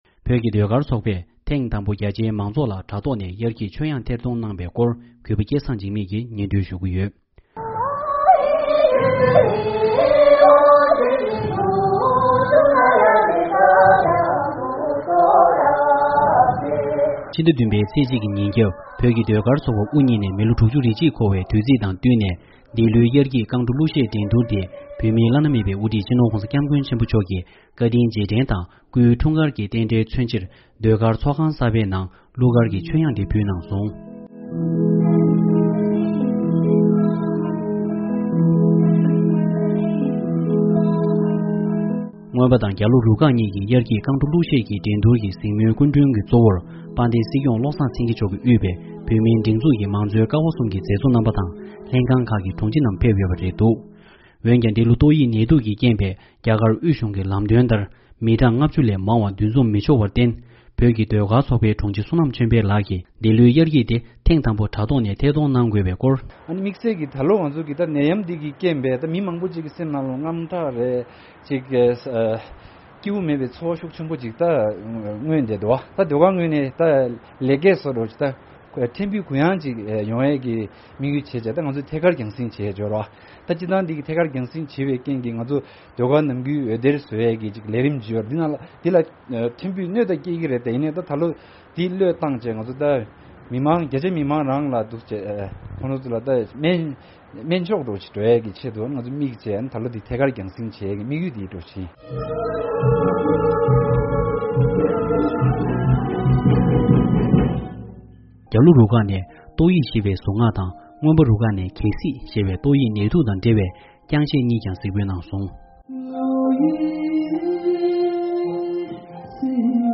༄༅།། ཕྱི་ཟླ་༧་པའི་ཚེས་༡་ཉིན་ནི་བོད་ཀྱི་ཟློས་སྒར་ཚོགས་པ་དབུ་བརྙེས་ནས་ལོ་༦༡་འཁོར་བའི་དུས་ཆེན་ཡིན་ཞིང་། ལོ་འདིའི་ཟློས་གར་ཚོགས་པའི་རྔོན་པ་དང་རྒྱལ་ལུའི་རུ་ཁག་ཟུང་གི་དབྱར་སྐྱིད་ཀྱི་རྐང་བྲོ་དང་གླུ་གཞས་འགྲན་སྡུར་རྣམས་བོད་ཀྱི་བླ་ན་མེད་པའི་དབུ་ཁྲིད་སྤྱི་ནོར་༧གོང་ས་སྐྱབས་མགོན་ཆེན་པོ་མཆོག་གི་བཀའ་དྲིན་རྗེས་དྲན་དང་སྐུའི་འཁྲུངས་སྐར་གྱི་རྟེན་འབྲེལ་མཚོན་ཕྱིར་ཟློས་སྒར་ཚོགས་ཁང་གསར་པའི་ནང་དུ་གླུ་གར་ཀྱི་མཆོད་དབྱངས་ཕུལ་ཡོད་པའི་སྐོར།